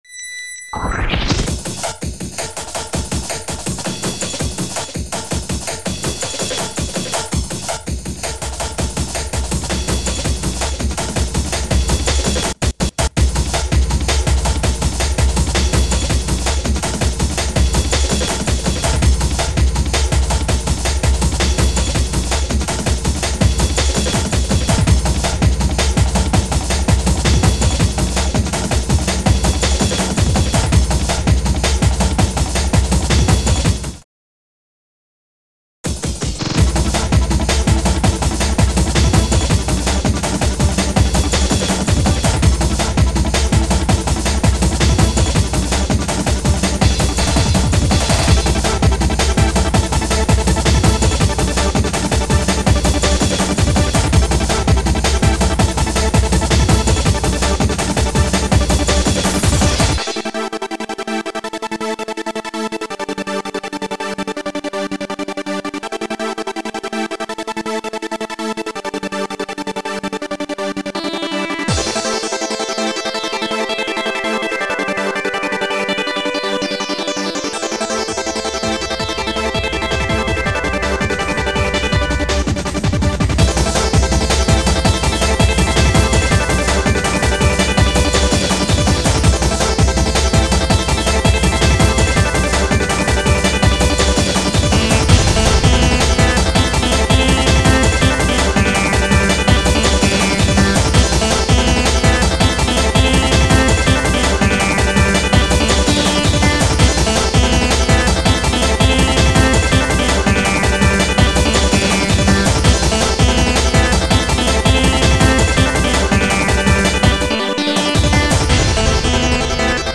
style:hmm.. techno!
speed:003/164bpm